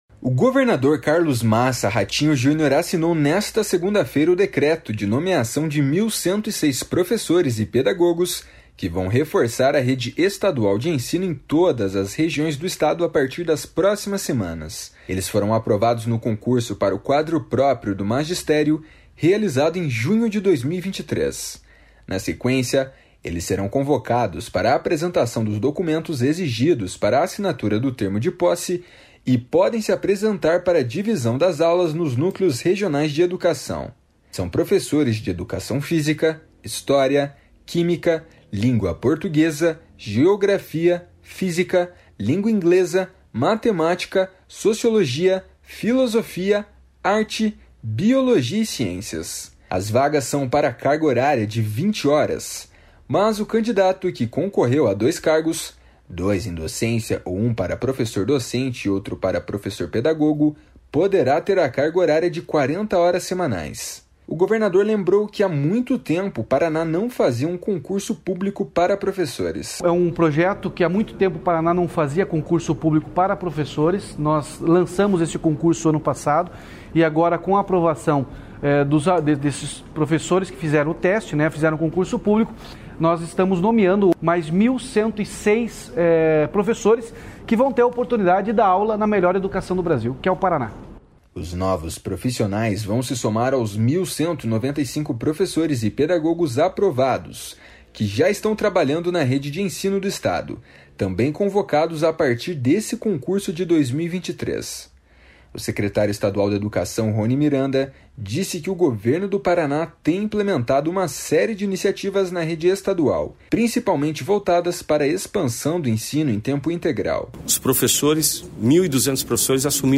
O governador lembrou que há muito tempo o Paraná não fazia um concurso público para professores. // SONORA RATINHO JUNIOR //